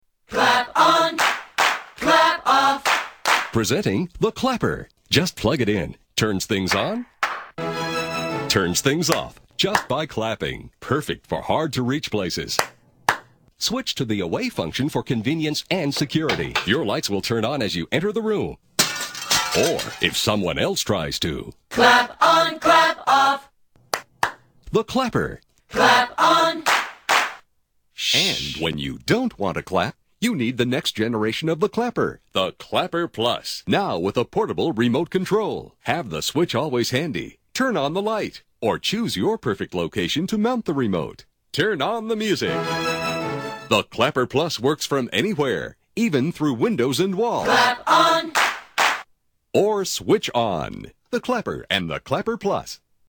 Tags: The Clapper The Clapper clips The Clapper sounds The Clapper ad The Clapper commercial